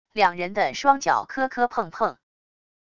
两人的双脚磕磕碰碰wav音频